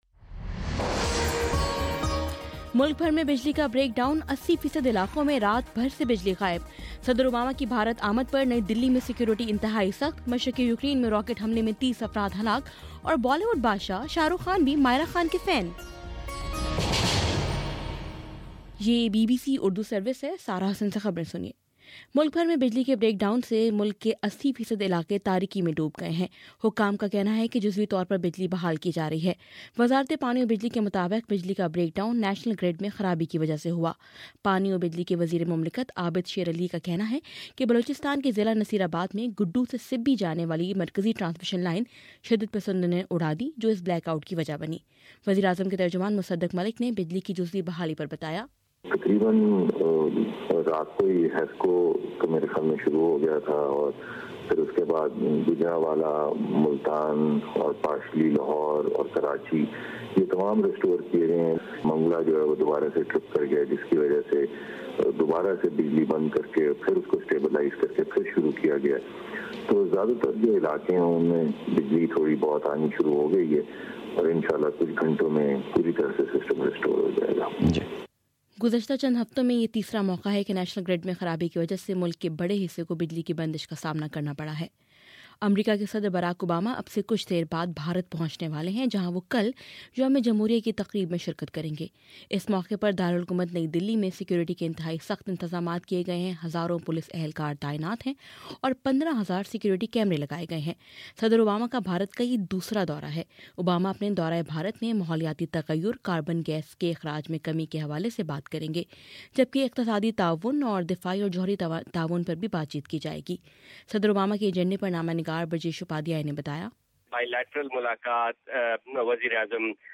جنوری25: صبح نو بجے کا نیوز بُلیٹن